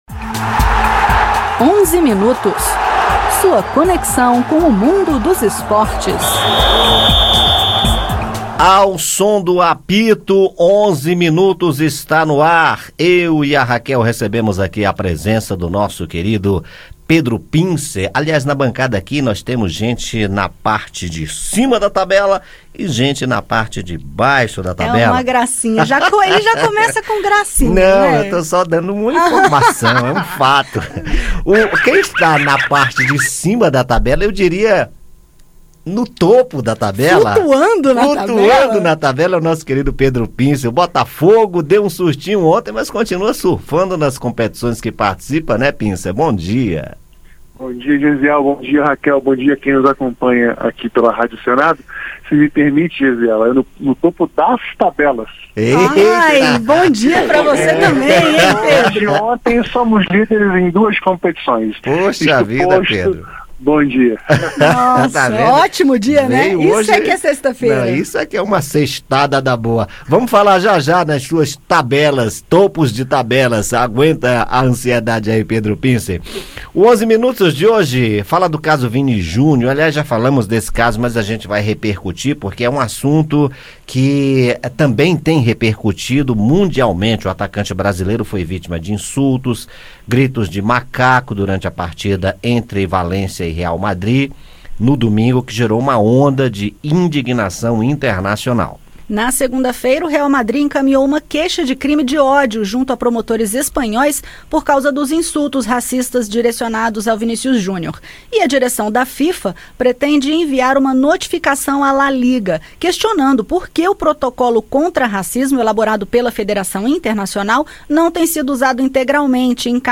Na edição, ouça os comentários sobre esporte e futebol com o jornalista convidado